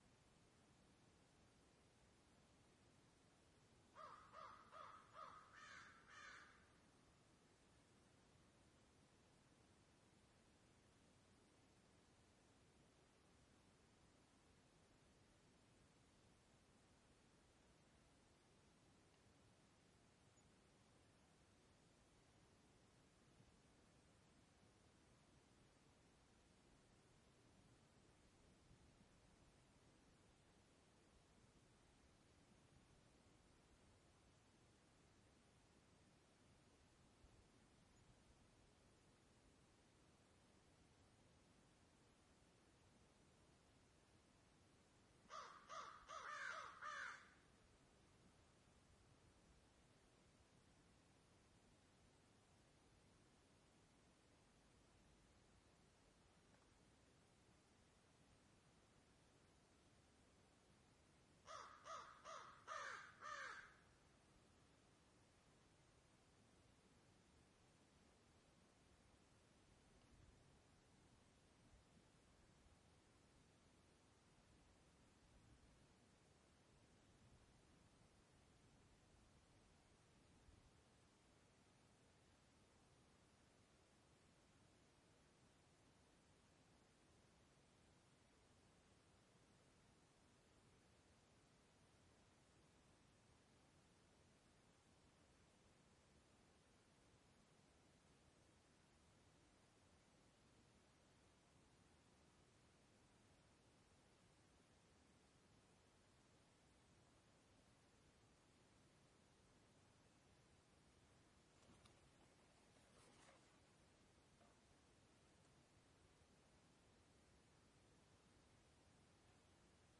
氛围 户外 冬季 湖边 极其安静的乌鸦
描述：在冰雪覆盖的湖边拍摄非常安静的录音。在远处可以听到乌鸦的声音。用H2N变焦记录仪记录。
标签： 场记录 鸟类 环境 乌鸦 冬季
声道立体声